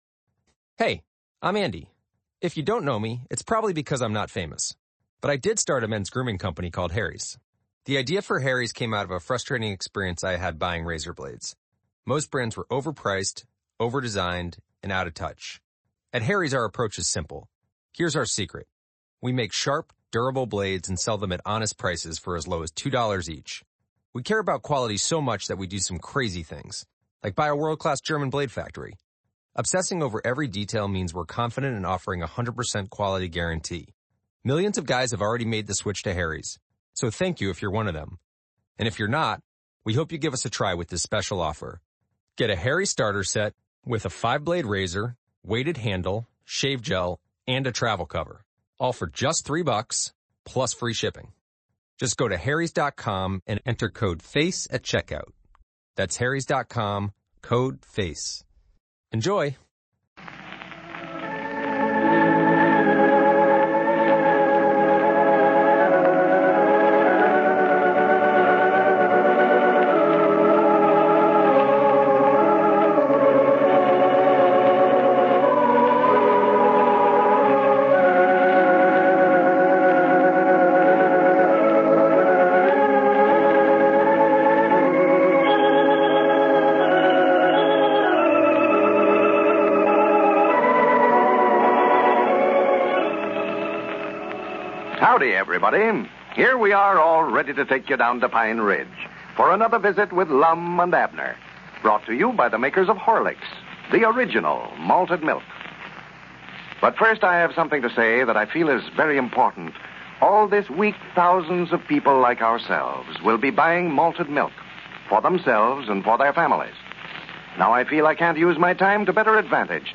Lum and Abner! A classic radio show that brought laughter to millions of Americans from 1931 to 1954.